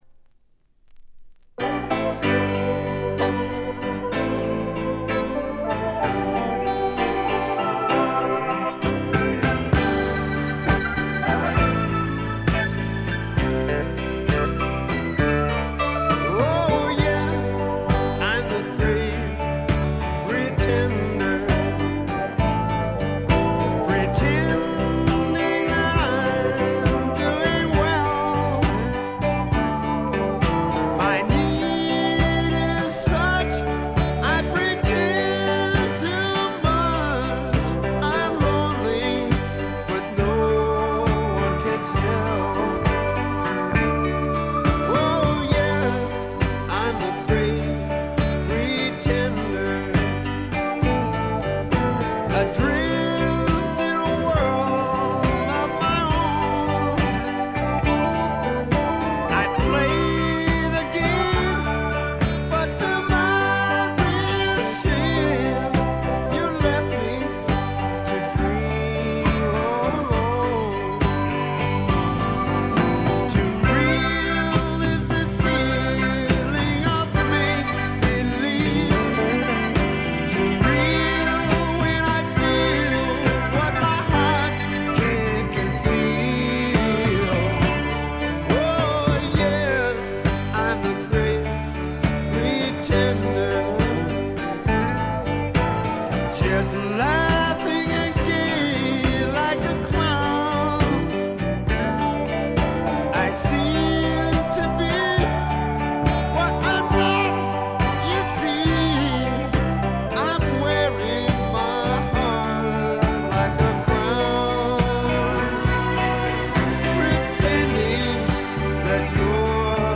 部分試聴ですが、微細なチリプチ程度でほとんどノイズ感無し。
試聴曲は現品からの取り込み音源です。